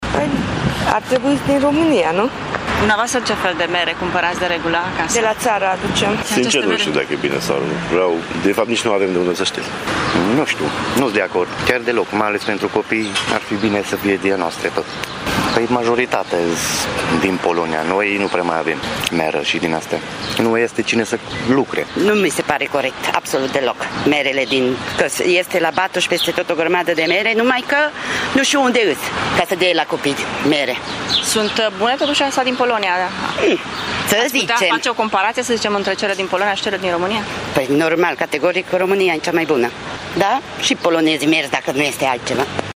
Părinții târgumureșeni cred că merele românești ar fi fost mult mai bune pentru elevi: